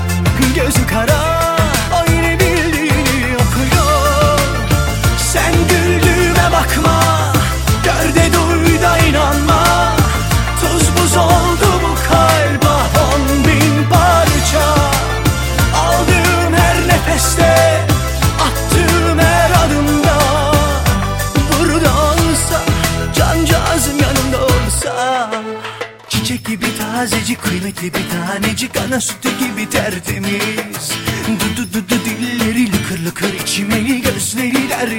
Жанр: Турецкая поп-музыка / Поп